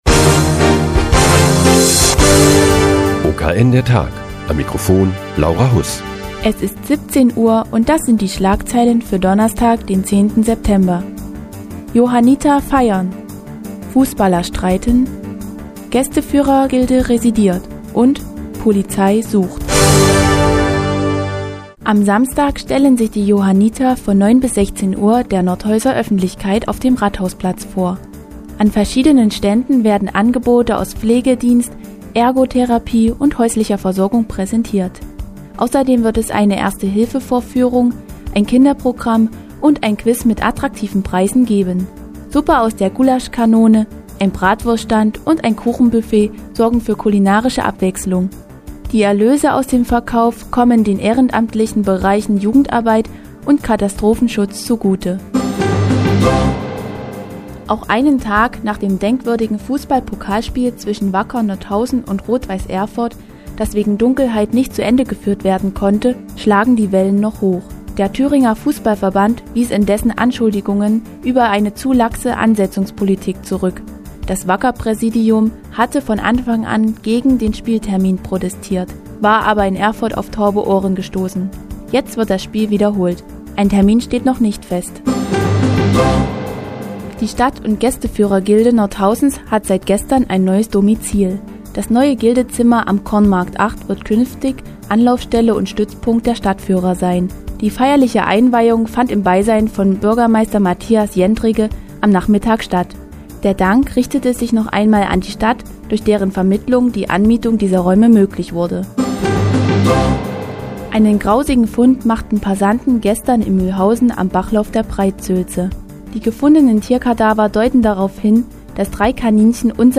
Die tägliche Nachrichtensendung des OKN ist nun auch in der nnz zu hören. Heute feiern die Johanniter, die Fußballer streiten, die Gästeführer residieren und die Polizei sucht.